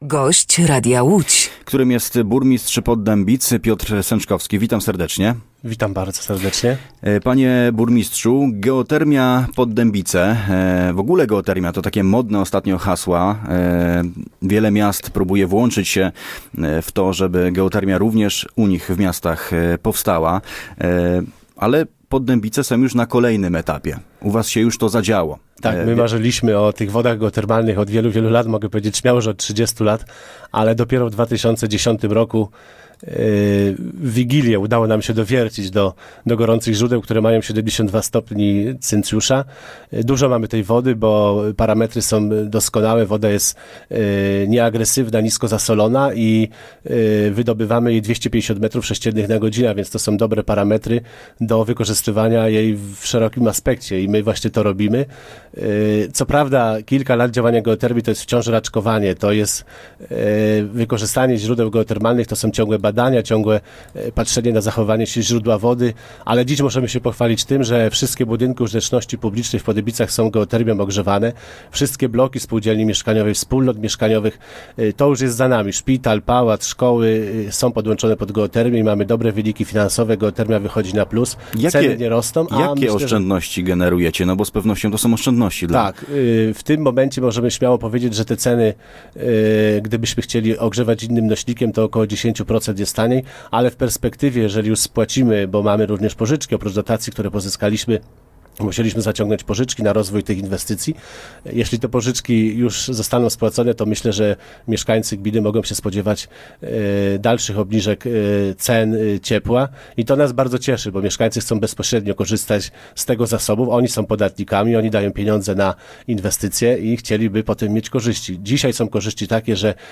Gościem Radia Łódź Nad Wartą był burmistrz Poddębic, Piotr Sęczkowski.